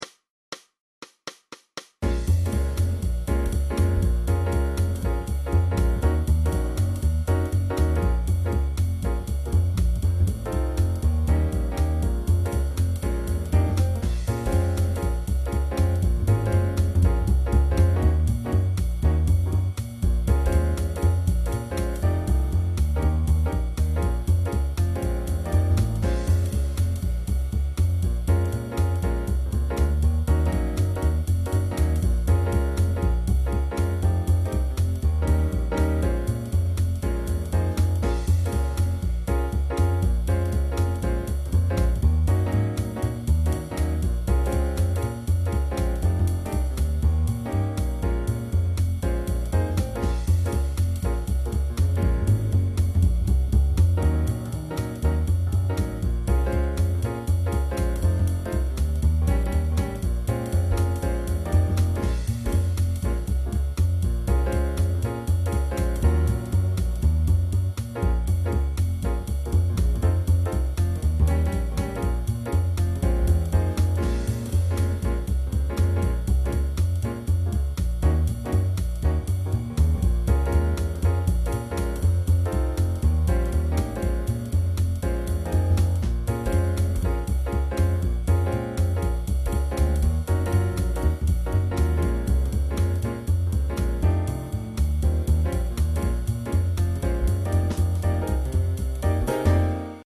High School Jazz Band Practice Tracks 2007-2008
Each tune has a two bar intro (drums)